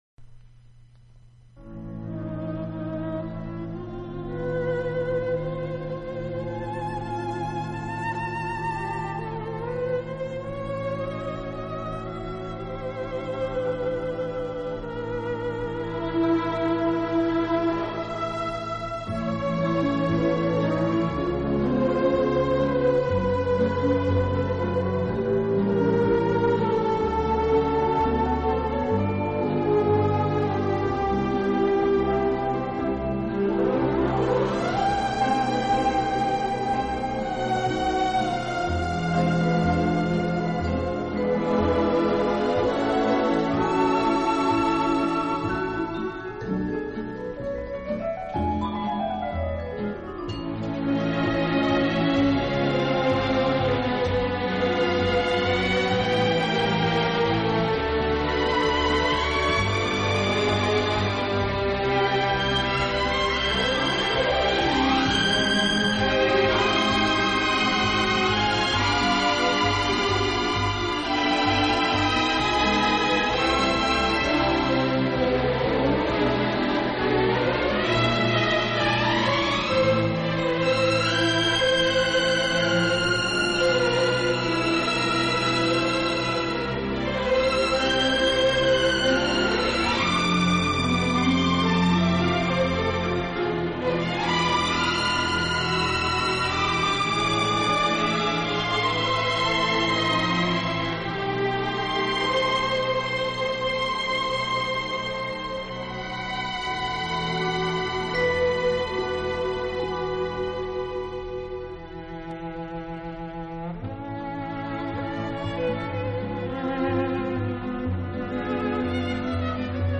【轻音乐】
【轻音乐专辑】